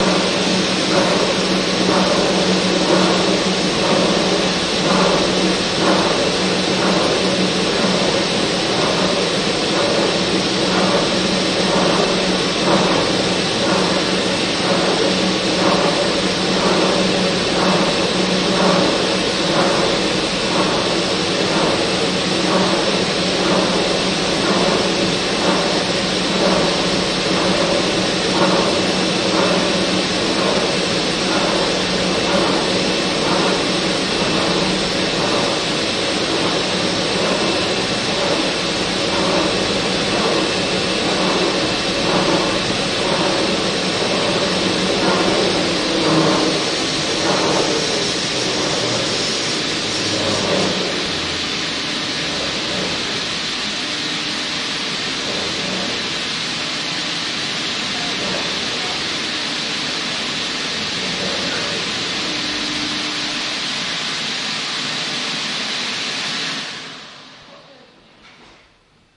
地铁
描述：这是阿伯里斯特威斯（Aberystwyth）缆索铁路缓缓爬到车站顶部的声音。
Tag: 悬崖 火车 铁路